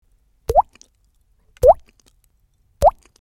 Download Water Drop sound effect for free.
Water Drop